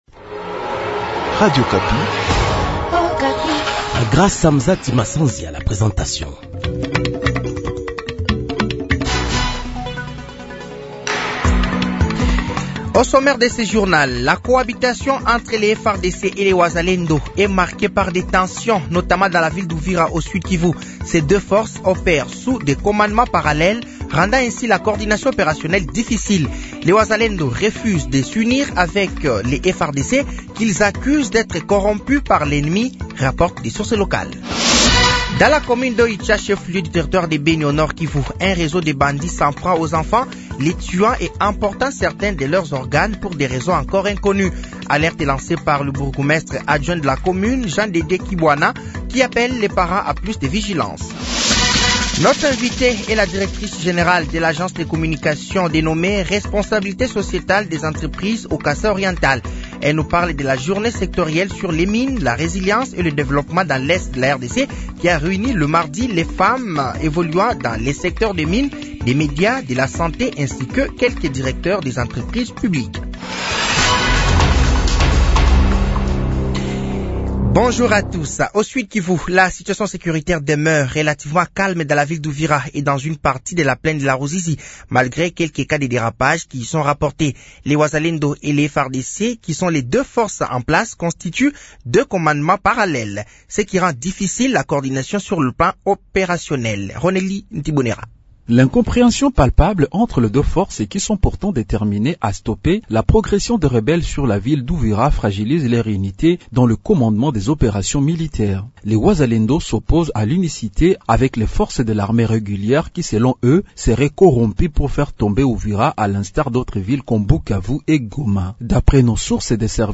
Journal français de 15h de ce samedi 29 mars 2025